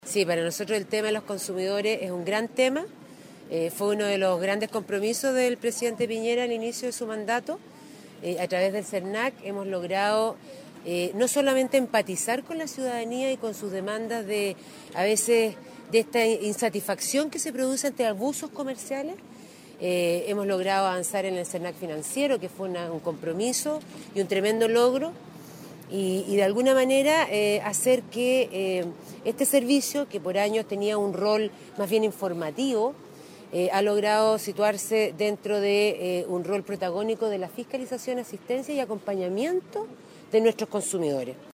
Escuchar aquí las palabras de la Intendenta de la Región de Aysén, Pilar Cuevas.